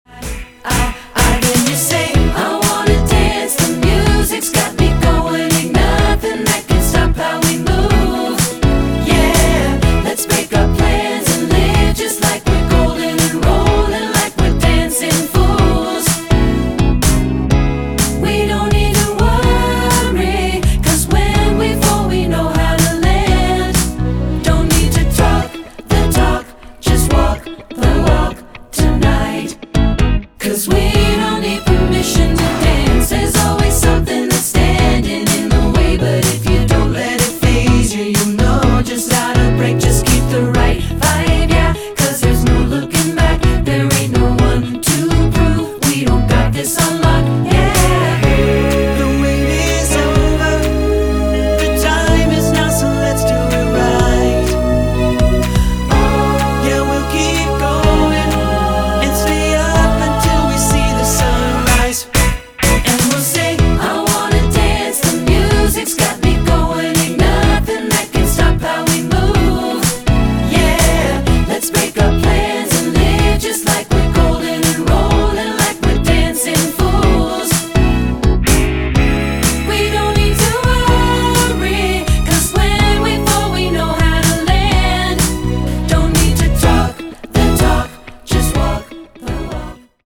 Choral Recent Pop Hits